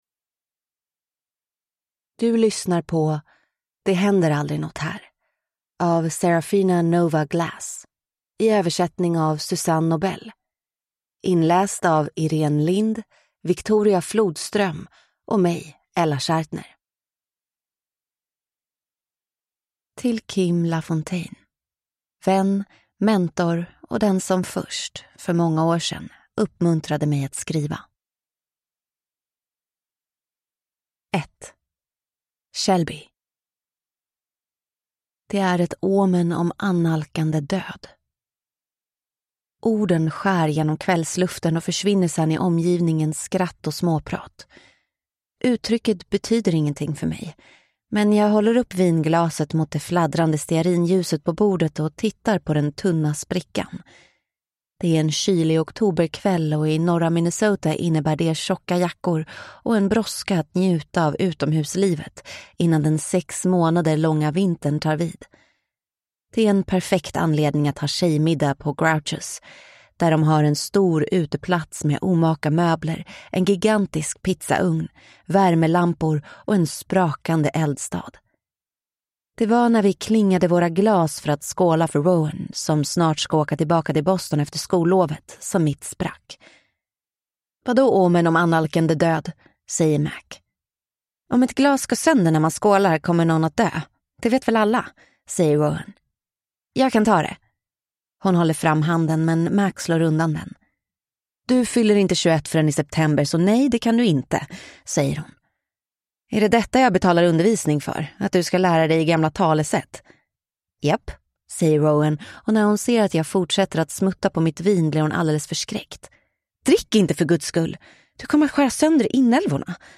Det händer aldrig något här (ljudbok) av Seraphina Nova Glass